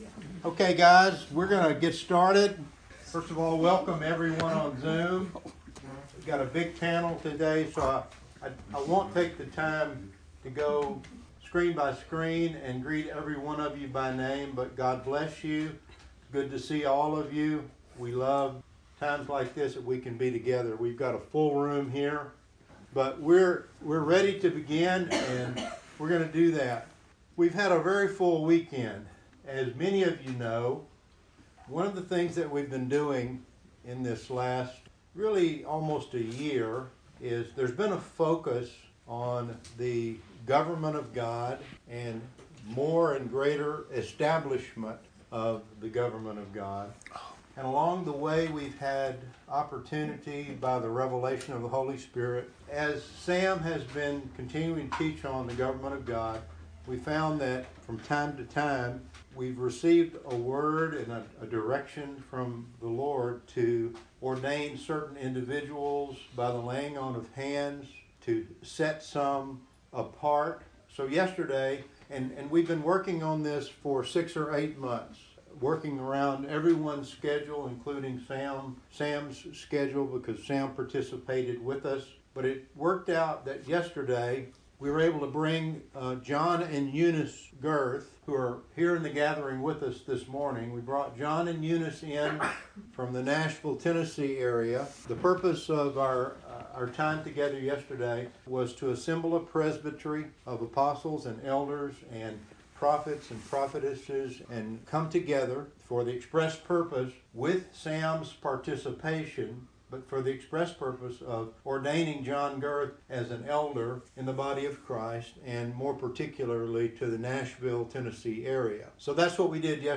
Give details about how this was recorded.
We had a wonderful gathering on Sunday, June 23rd.